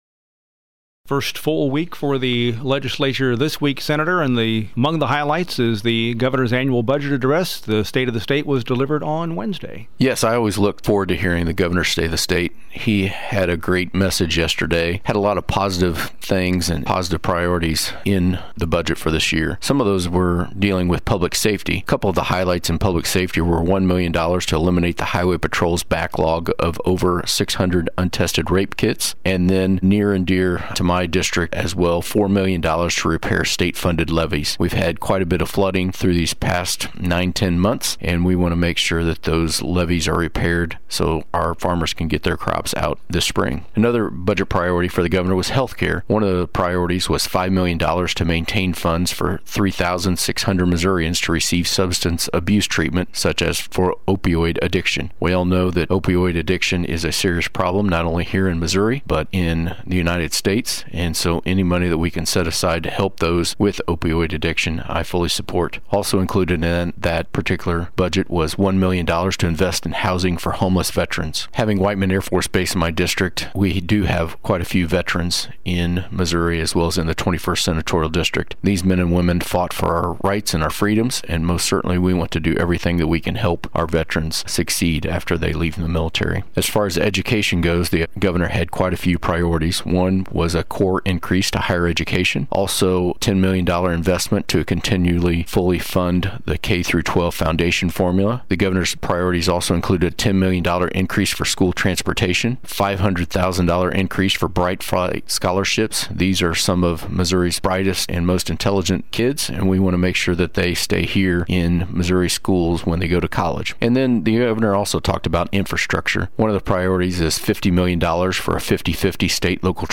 JEFFERSON CITY — State Sen. Denny Hoskins, R-Warrensburg, discusses his reaction to the governor’s annual budget address, more commonly known as the State of the State.
1. Senator Hoskins says two of the priorities expressed in the governor’s State of the State Address this week would affect people’s lives directly.